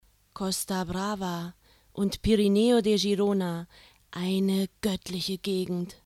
德语样音试听下载